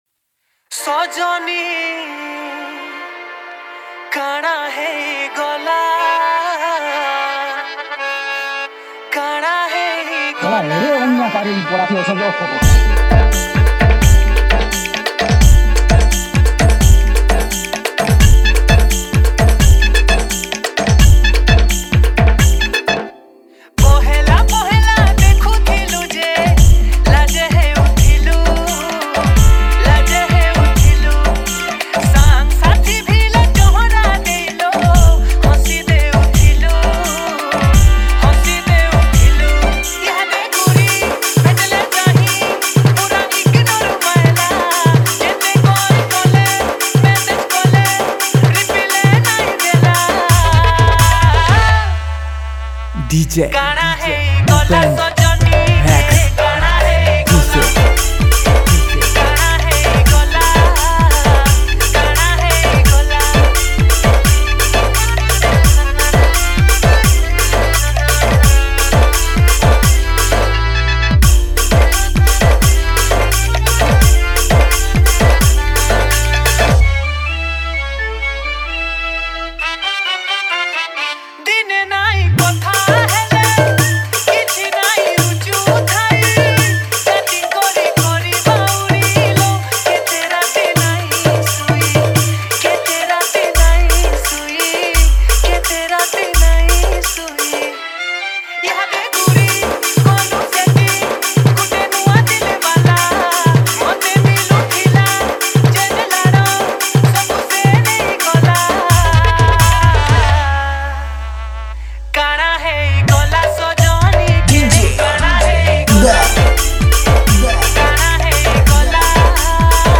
• Category: New Sambalpuri DJ Song 2025